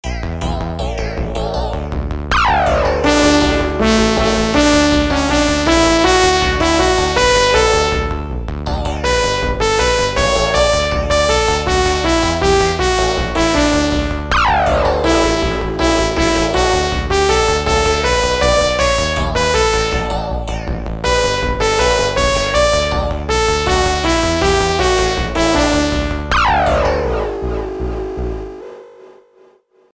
SX-150をサンプリング演奏してみる
問題はピッチがとりにくいということだから、SX-150 の出音をサンプリングしてサンプラーで演奏してみたら良いんじゃね。と実験してみた。
全部の音(今回は5種類)を SX-150 からとって、FL Studio のSimpleSampler で鳴らしている。
音は全部SX-150だけれども、DAWの上でエフェクターはバリバリかけているのでそのままというわけでもなく。
どうも、ベースやエレピっぽい音を鳴らしたときにLFOがちょい混じったらしく変な感じが残っているあたりが失敗。でもメロディはそれっぽいかと。